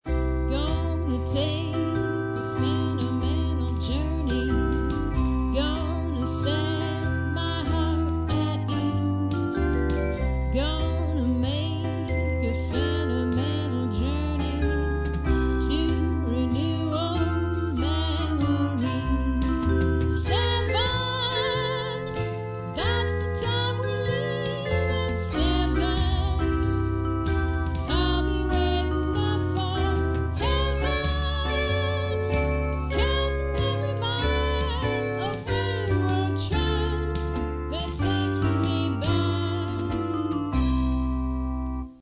With Background Music on Sequencer